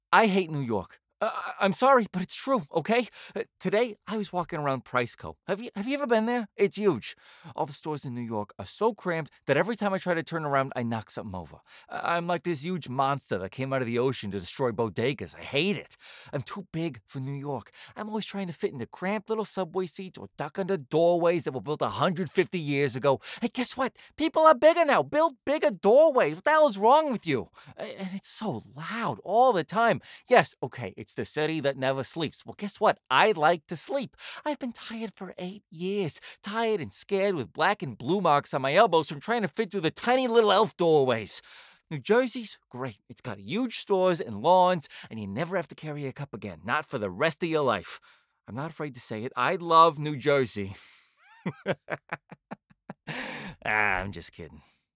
new york | natural
New_York.mp3